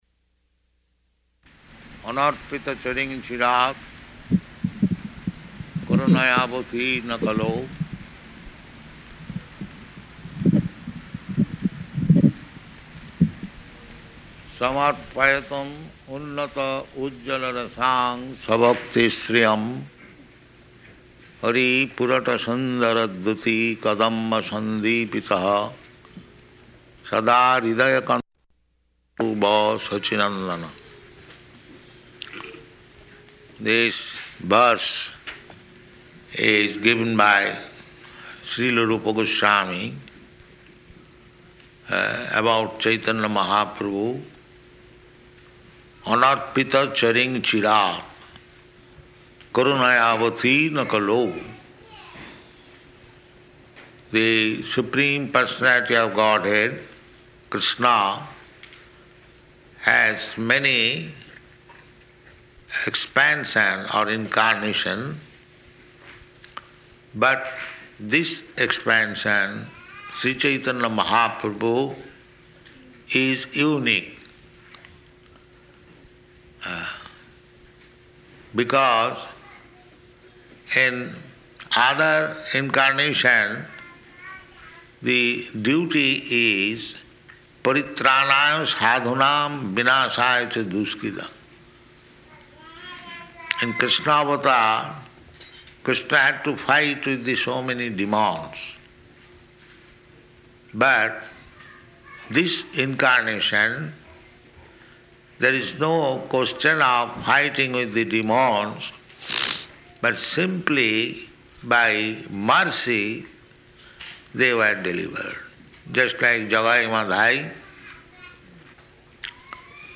Lecture and Initiation
Type: Initiation
Location: Chicago